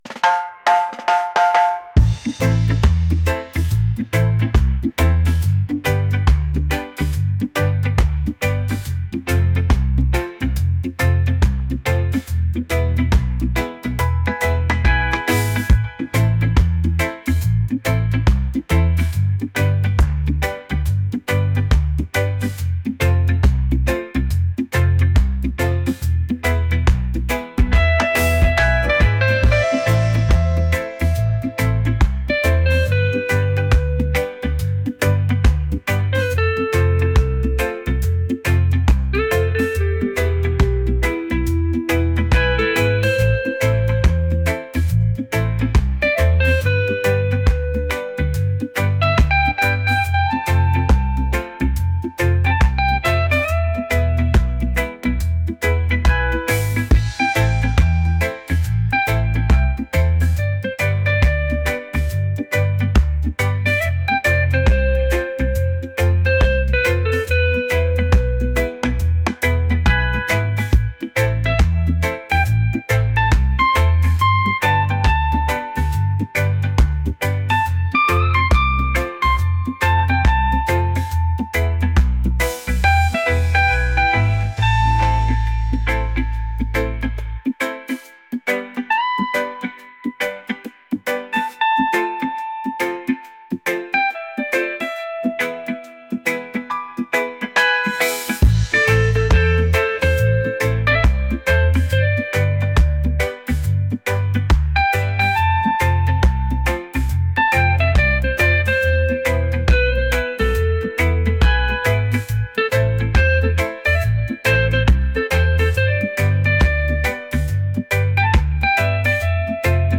soulful | reggae